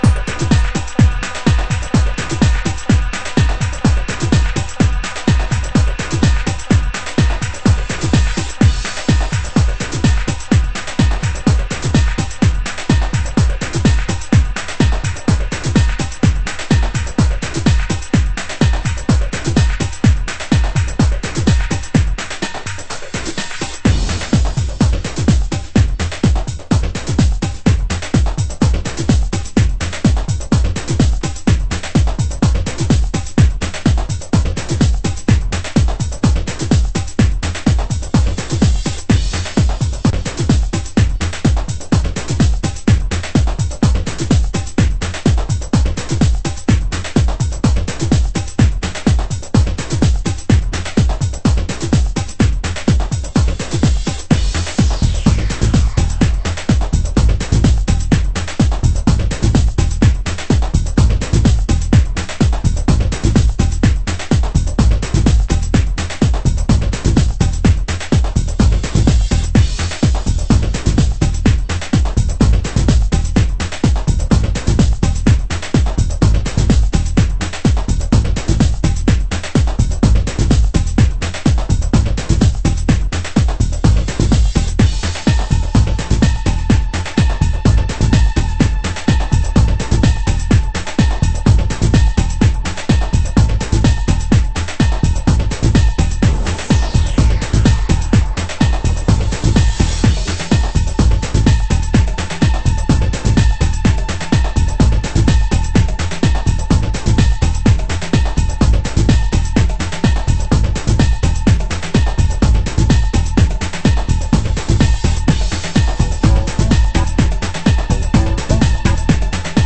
HOUSE MUSIC
盤質：イントロ等に少しチリノイズ有/B2に盤面汚れによる「針飛び」 有　　　ジャケ：良好